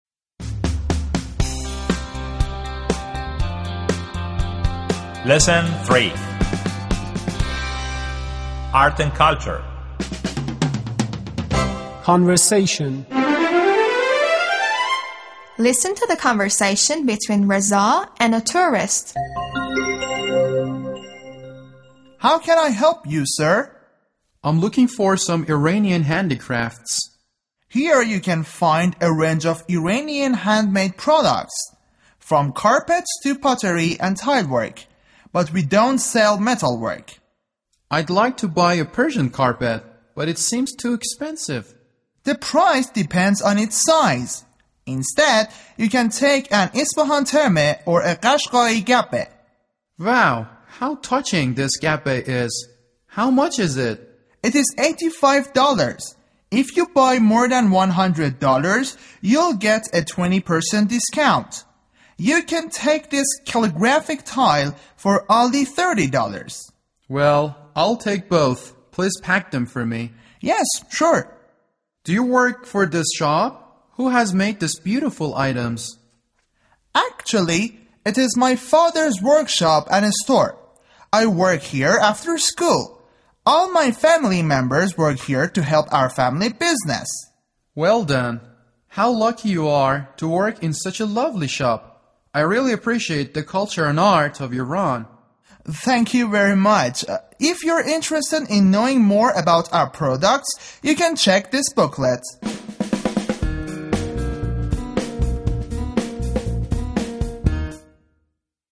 Conversation
V.2-L.3-conversation.mp3